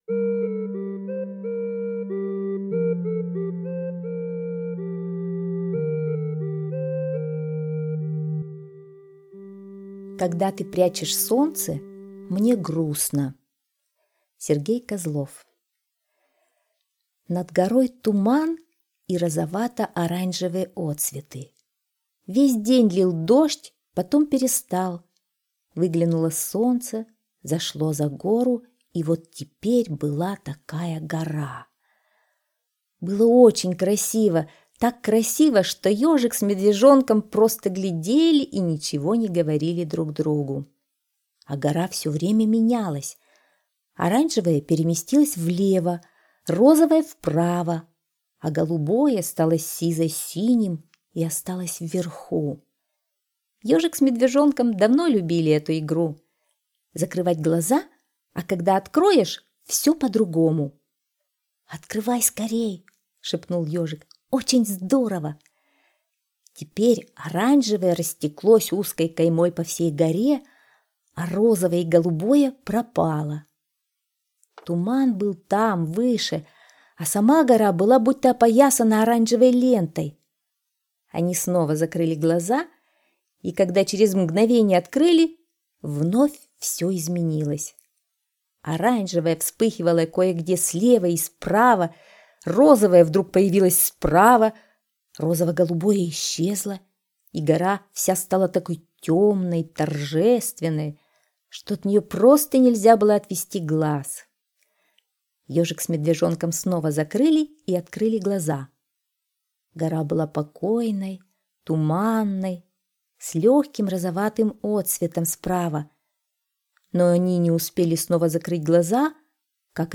Слушайте Когда ты прячешь солнце, мне грустно - аудиосказка Козлова С.Г. Сказка про Ежика и Медвежонка, которые любили смотреть на гору на закате.